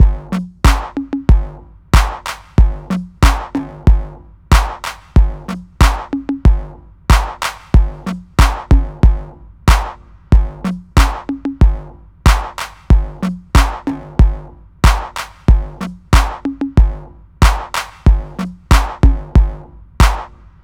08 drums A.wav